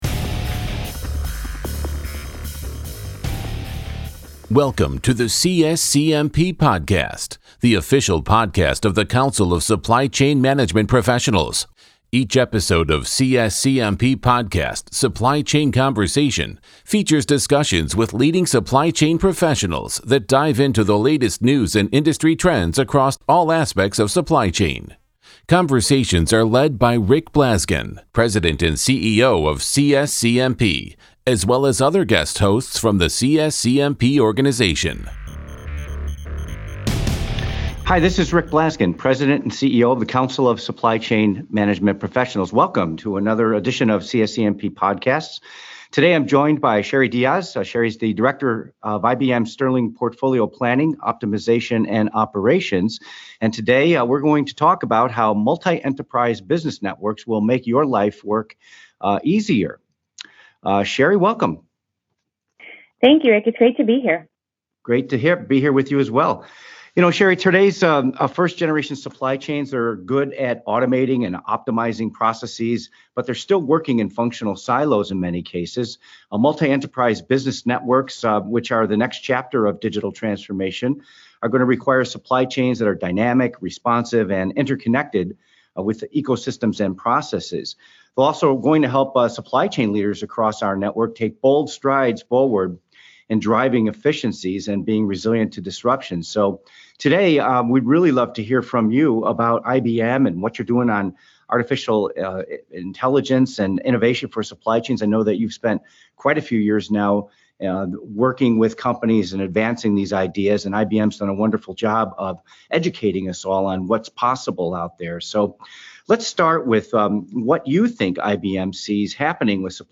Hear the whole interview with Daimler